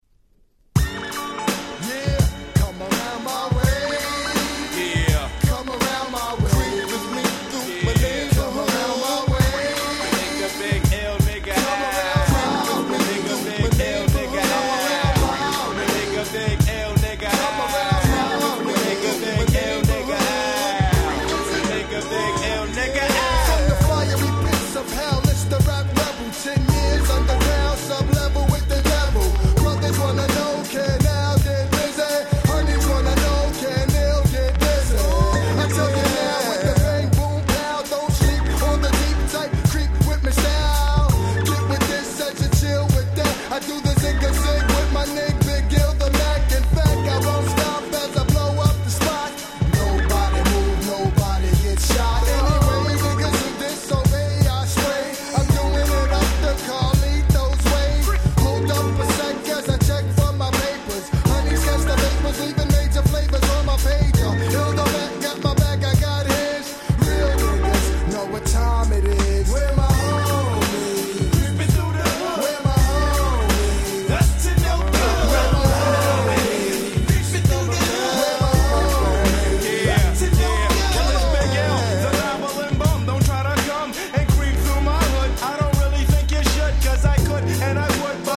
94' Smash Hit Hip Hop !!
90's Hip Hop Classics !!
このGroove感、これこそがまさに90's Hip Hop !!
イルオルスクラッチ Boom Bap ブーンバップ 90's